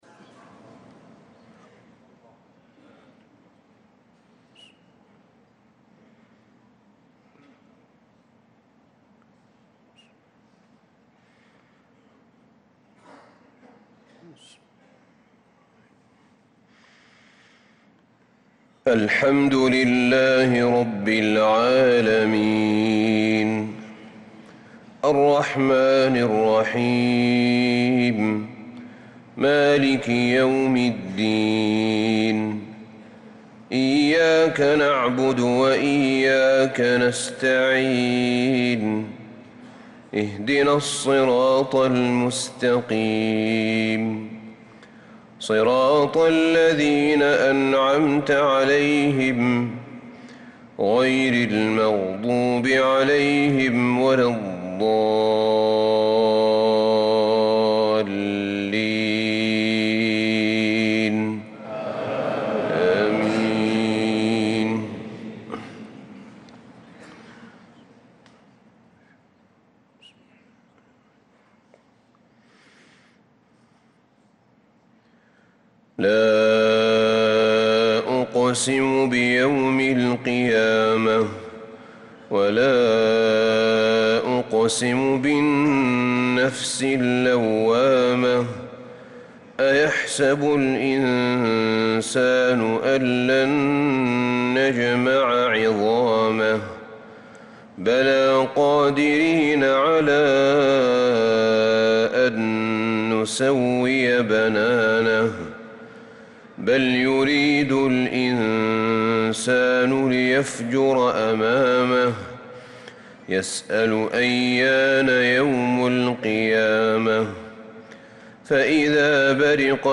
صلاة الفجر للقارئ أحمد بن طالب حميد 17 ربيع الآخر 1446 هـ
تِلَاوَات الْحَرَمَيْن .